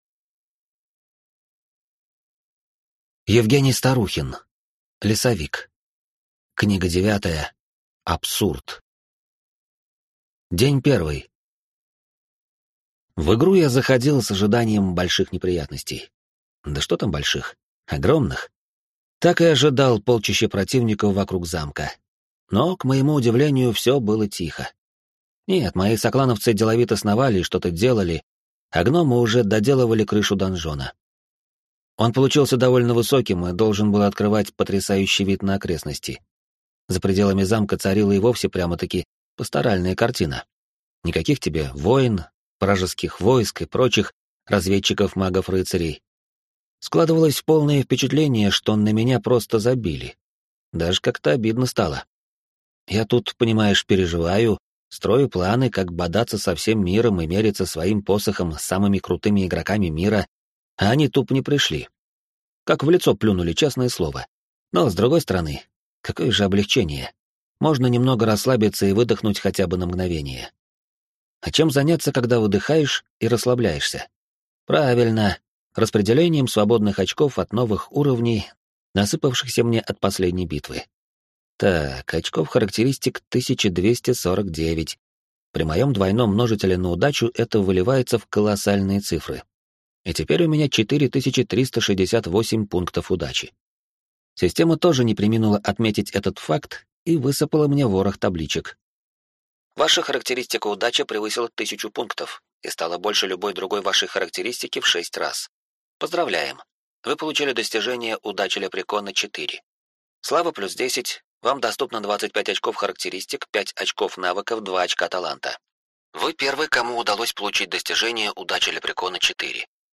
Аудиокнига Лесовик. Абсурд | Библиотека аудиокниг